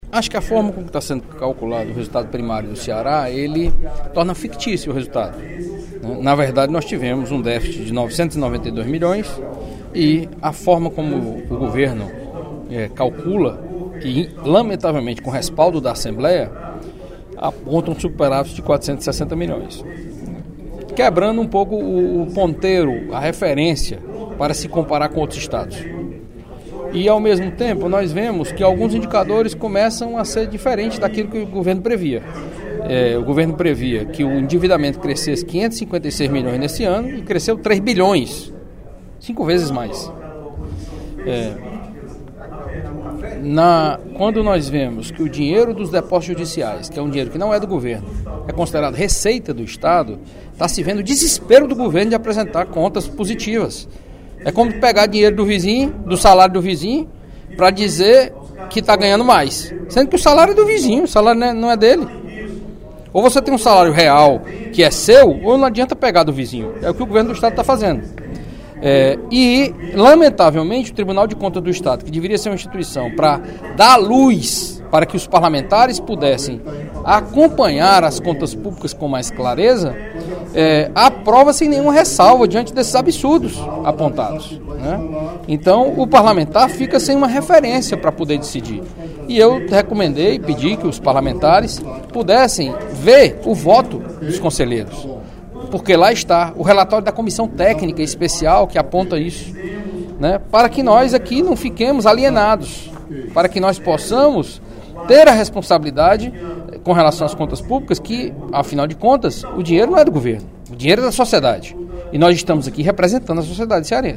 O deputado Carlos Matos (PSDB) pediu, nesta terça-feira (14/06), durante o primeiro expediente da sessão plenária, a transparência nas contas do Estado e criticou a atuação do Tribunal de Contas do Estado (TCE) ao aprová-las sem ressalvas.